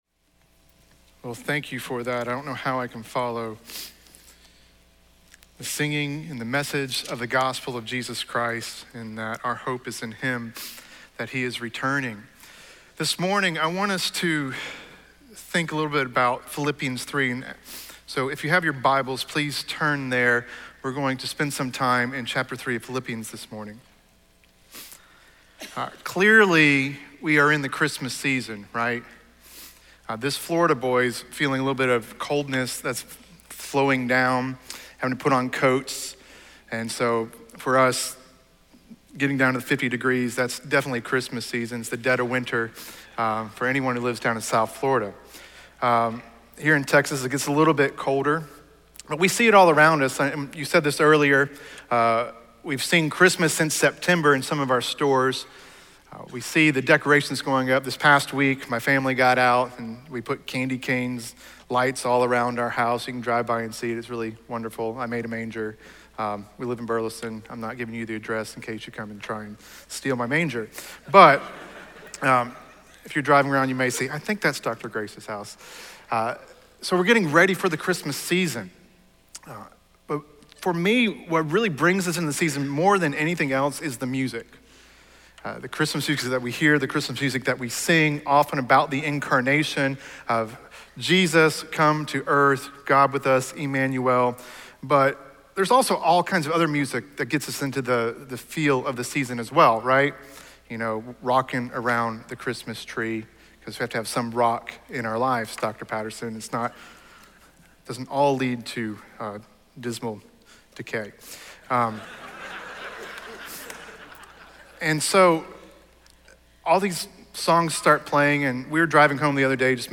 speaking on Philippians 3:2-11 in SWBTS Chapel